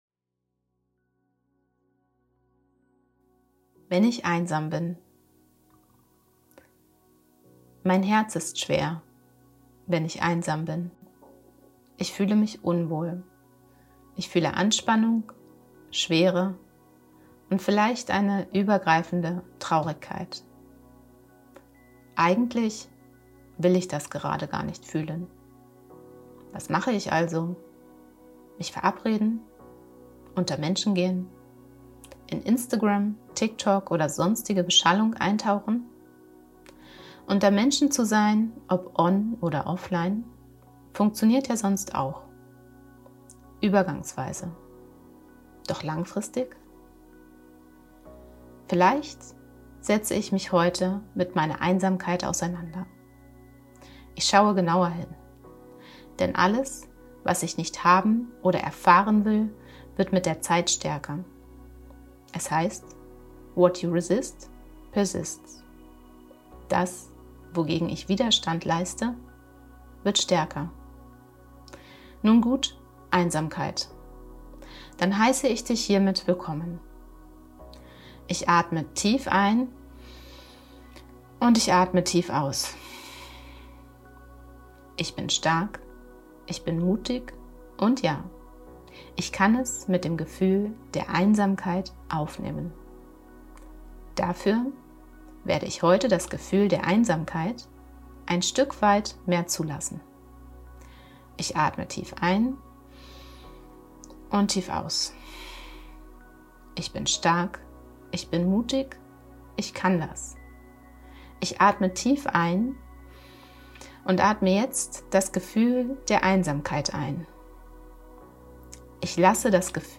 Wenn-ich-einsam-bin_mit-Musik.mp3